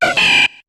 Cri de Poussifeu dans Pokémon HOME.